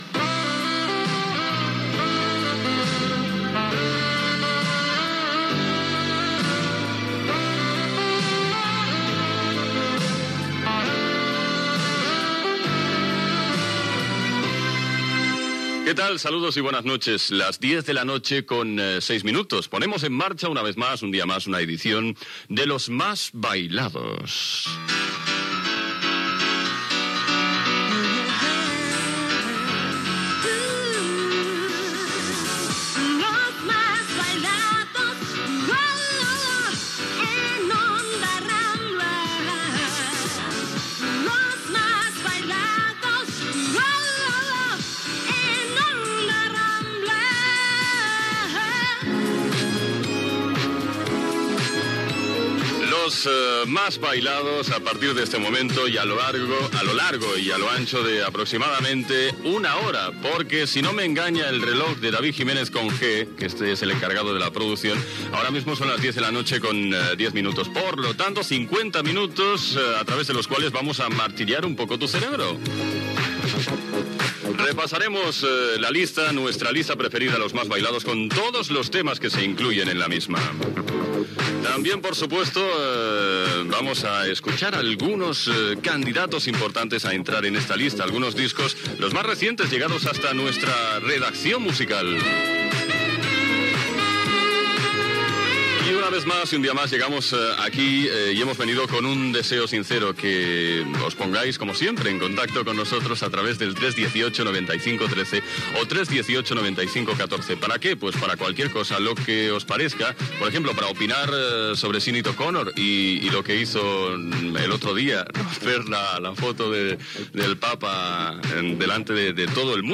Sintonia de l'emissora, hora, careta del programa, hora, presentació, telèfon de contacte, la presència en propers programes d'un DJ, repàs als 20 temes de la llista
Musical
FM